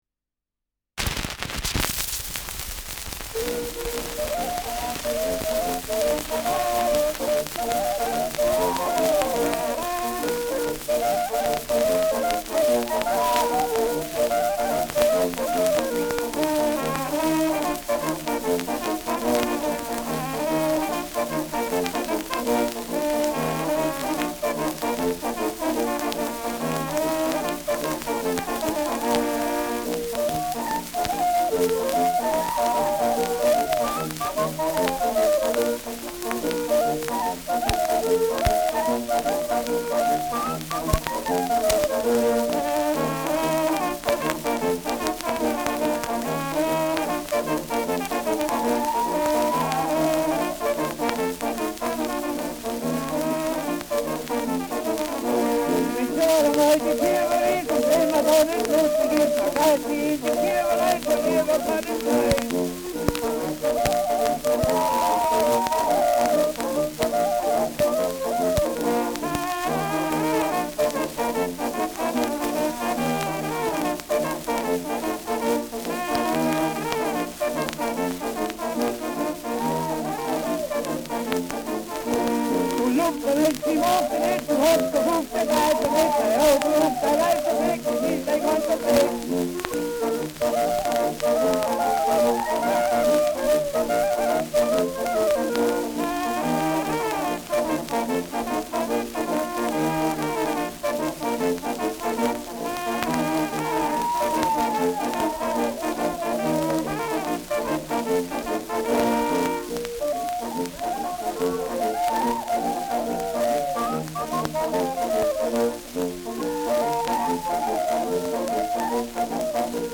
Wenn’s Jahr amal die Kirwa is : Galopp mit Gesang [Wenn im Jahr einmal die Kirchweih ist : Galopp mit Gesang]
Schellackplatte
Starkes Grundrauschen : Durchgehend leichtes bis stärkeres Knacken : Leiern : Verzerrt an lauteren Stellen
Kapelle Gömmel und Wolf, Neukirchen (Interpretation)